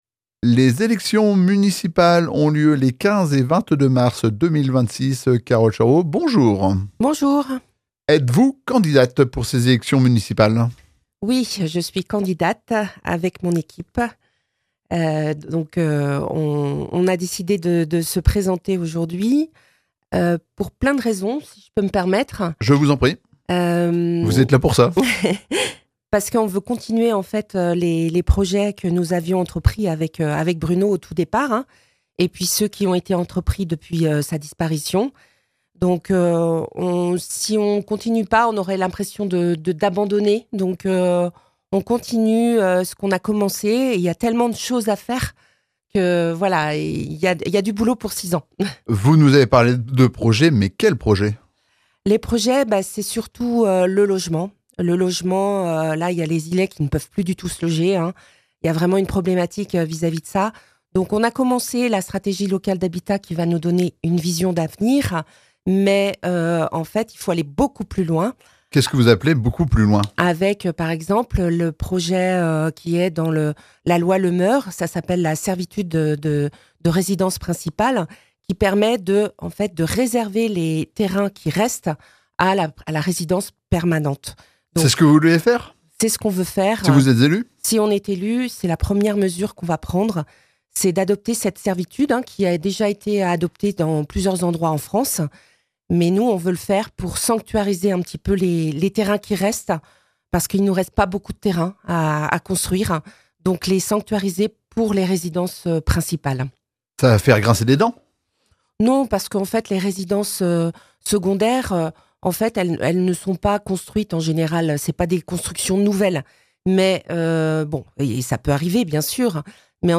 Nous terminons notre série d’interviews consacrées aux élections municipales des 15 et 22 mars 2026 à l’Île d’Yeu.
Pour cette troisième rencontre, nous recevons Carole Charuau, maire sortante et tête de liste “Une île en commun”.Il s’agit d’une interview de présentation, au cours de laquelle elle revient sur son engagement, la constitution de sa liste, sa vision de l’île et les grandes lignes de sa démarche.